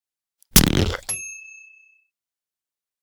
Beer Bottle/Soda Bottle (Twist Off) Opened Sound Effect
BeerBottleTwistOff.mp3